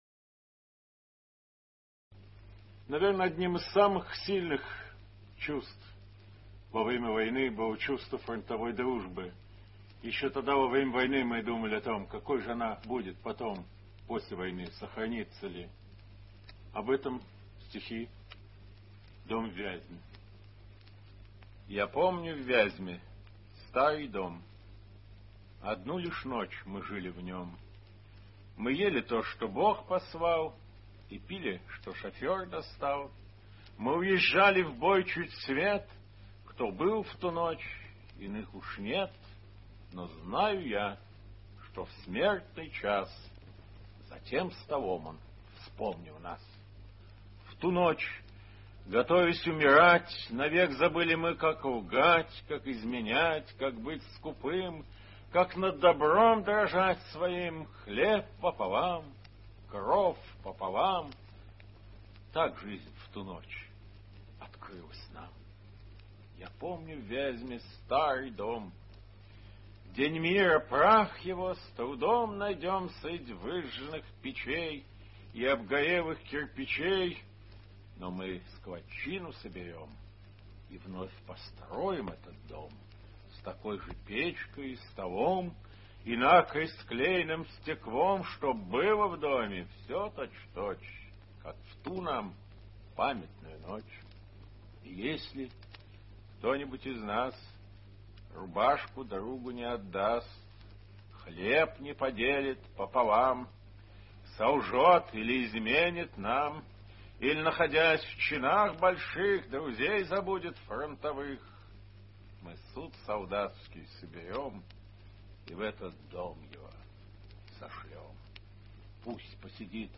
Simonov-Dom-v-Vyazme-chitaet-avtor-stih-club-ru.mp3